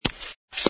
sfx_fturn_male03.wav